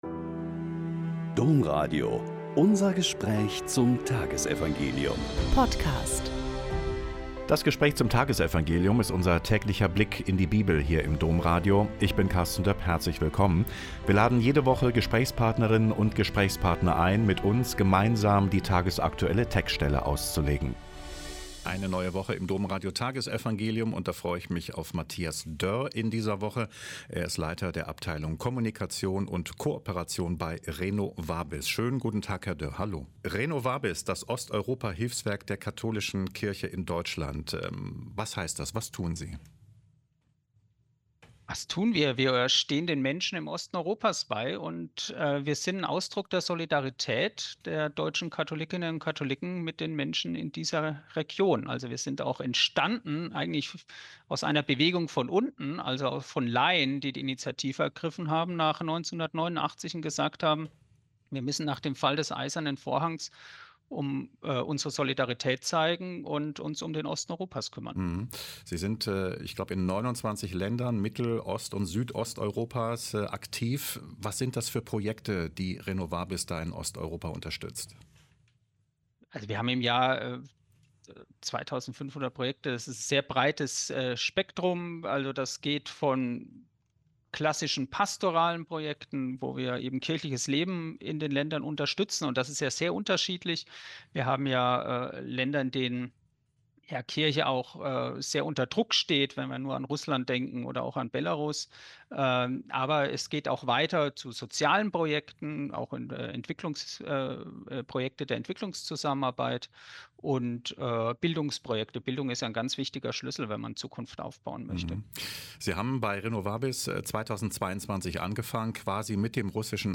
Lk 13,10-17 - Gespräch